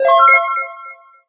Positive cue indicating success, can be an alternative entry chime.